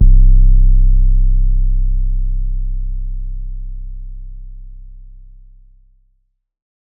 808 [Murda].wav